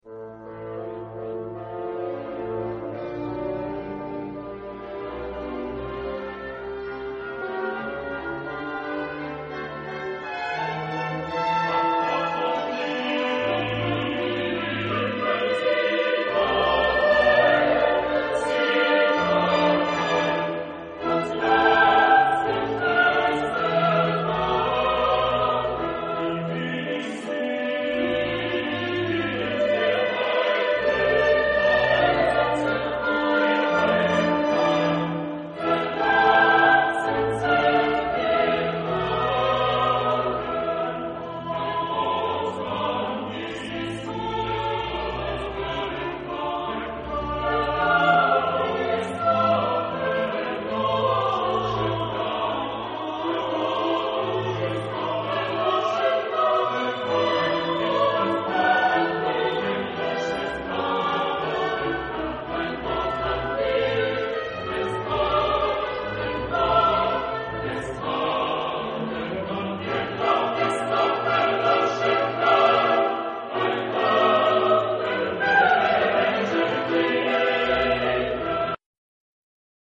Genre-Style-Forme : Sacré ; Romantique ; Cantate
Type de choeur : SATB  (4 voix mixtes )
Solistes : Bass (1)  (1 soliste(s))
Instrumentation : Orchestre
Instruments : Hautbois (2) ; Clarinette (2) ; Cor (2) ; Trombone (2) ; Violon (2) ; Alto (1) ; Violoncelle (1) ; Contrebasse (1)